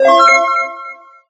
Chime2.ogg